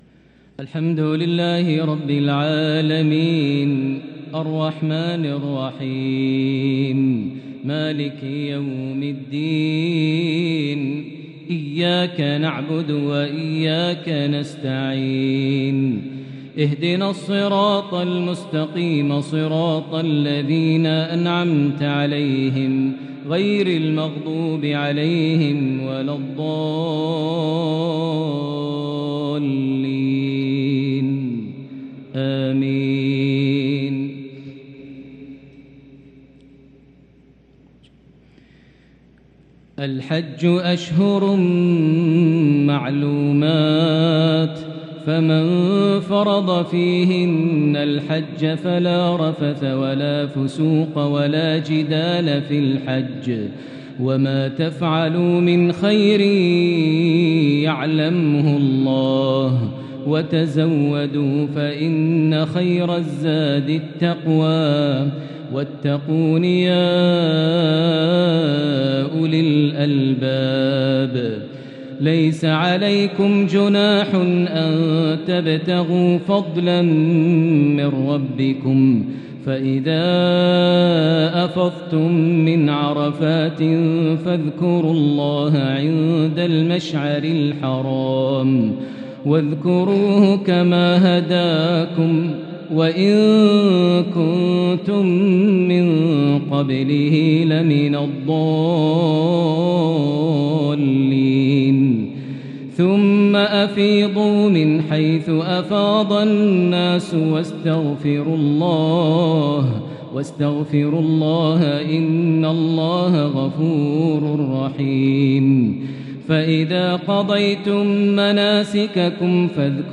lsha 6-2-2022 prayer from Surah Al-Baqara 197-207 > 1443 H > Prayers - Maher Almuaiqly Recitations